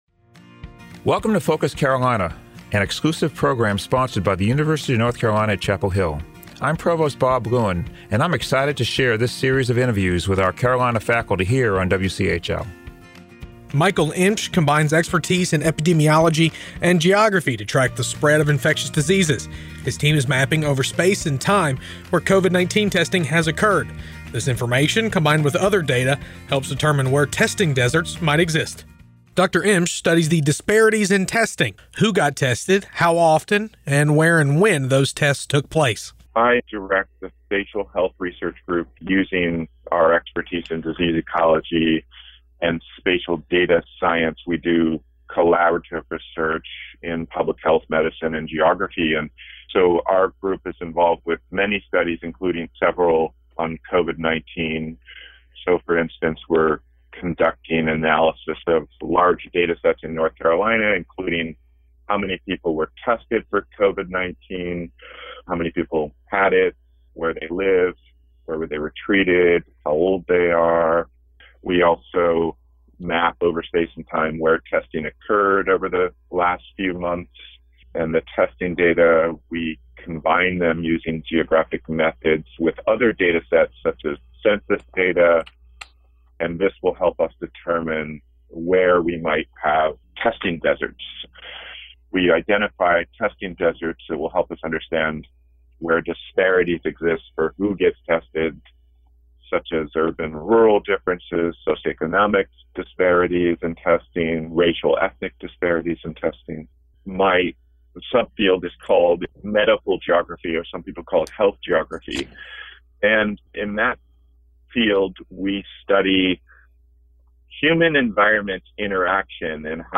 Tune in to Focus Carolina during morning, noon and evening drive times and on the weekends to hear stories from faculty members at UNC and find out what ignites their passion for their work. Focus Carolina is an exclusive program on 97.9 The Hill WCHL, sponsored by the University of North Carolina at Chapel Hill.